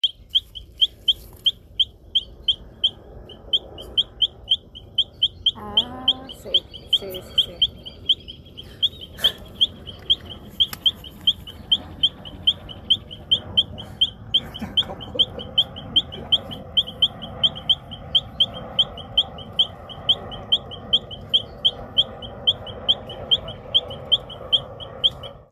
Rana Piadora (Leptodactylus latinasus)
Localización detallada: Área Natural Yrigoyen (Yrigoyen y el Río)
Condición: Silvestre
Certeza: Vocalización Grabada